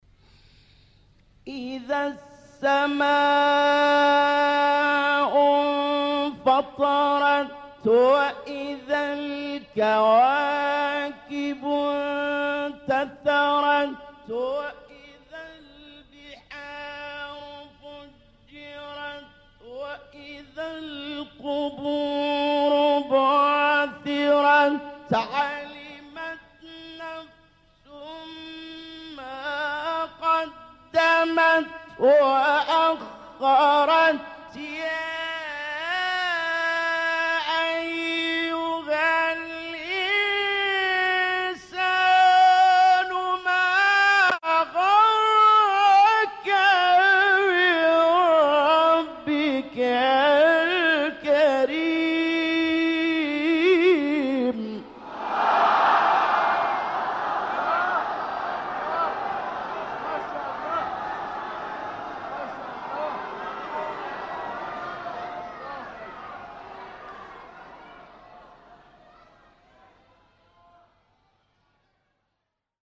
قطعات شنیدنی از تلاوت سوره مبارکه انفطار را با صوت قاریان محمد اللیثی، شحات محمد انور، عبدالباسط محمد عبدالصمد، محمد صدیق منشاوی و راغب مصطفی غلوش می‌شنوید.
آیات ۱ تا ۶ سوره انفطار با صوت محمد اللیثی